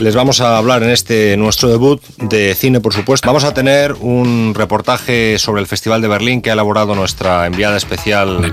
Presentació de la primera edició del programa